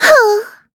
Taily-Vox_Sigh.wav